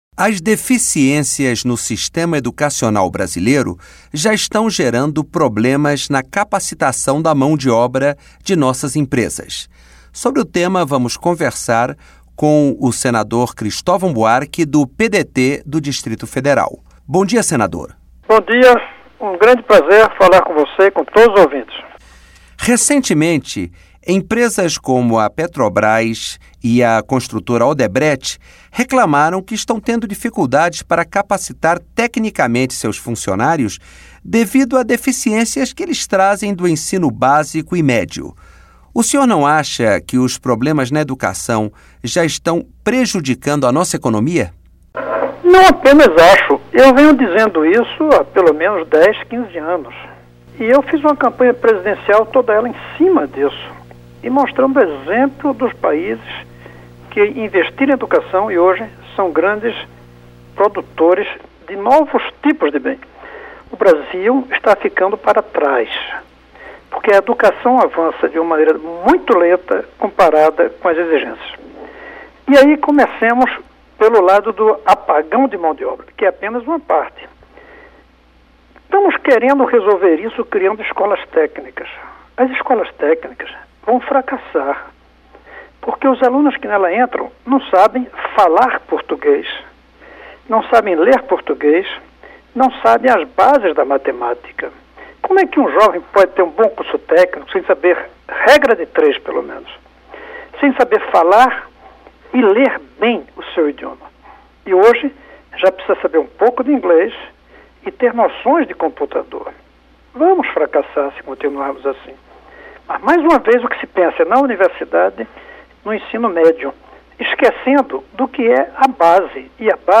Entrevista com o senador Cristovam Buarque (PDT-DF).